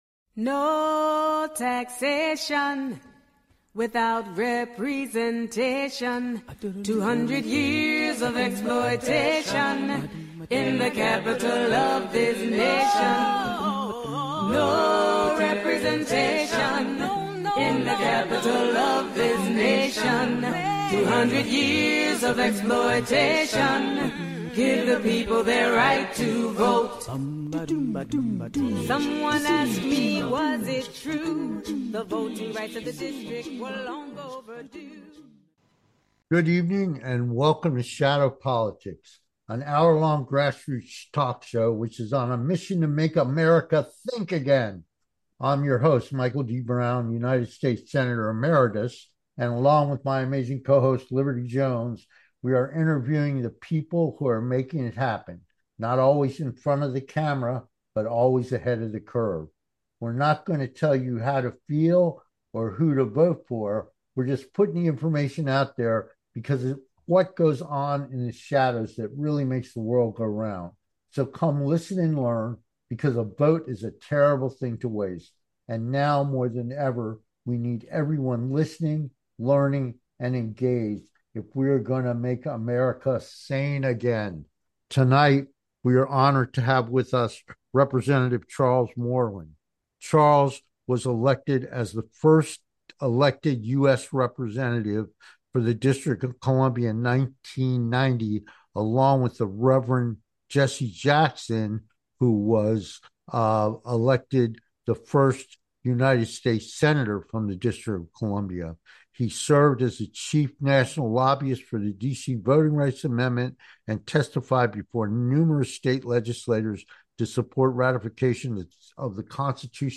Talk Show Episode, Audio Podcast, Shadow Politics and Guest, Charles Moreland 1st US Representative for DC - The Legacy of Activism on , show guests , about DC Statehood,Legacy of Activism,Charles Moreland,Apartheid,Civil Rights, categorized as Entertainment,History,News,Politics & Government,Local,National,Society and Culture
Guest, Charles Moreland 1st US Representative for DC - The Legacy of Activism